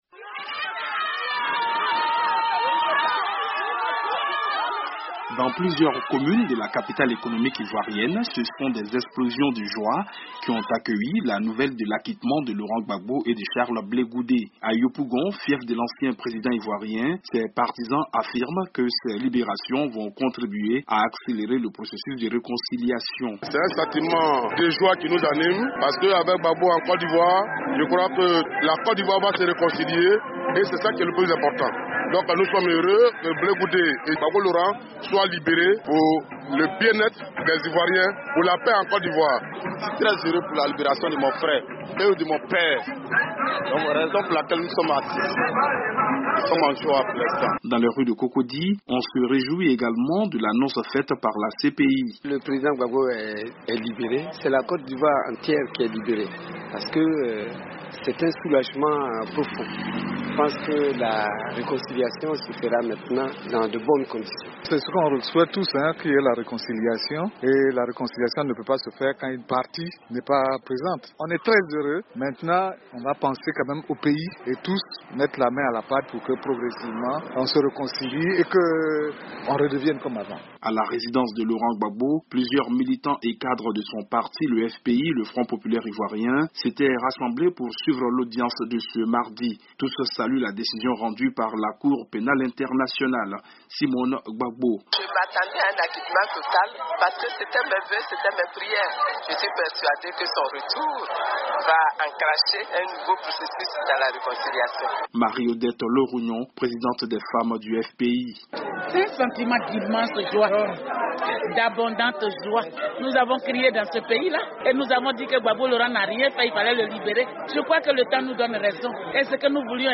L'analyse de la libération de Gbagbo par notre correspondant